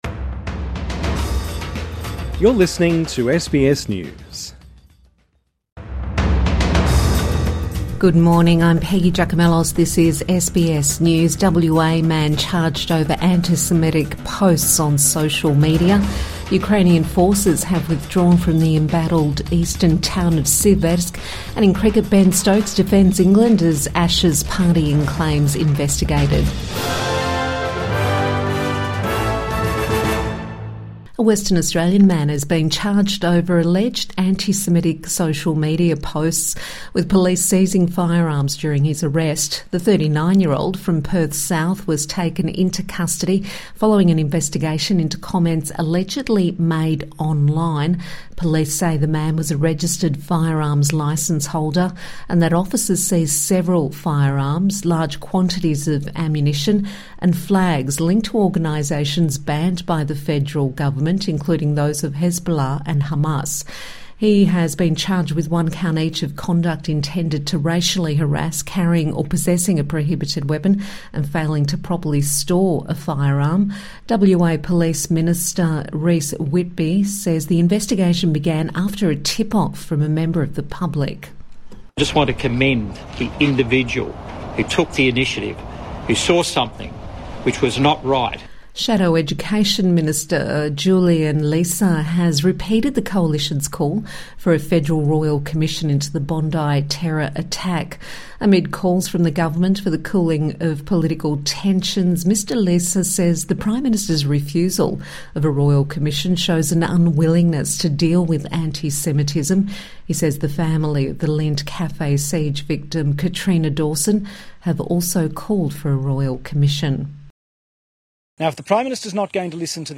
SBS News Updates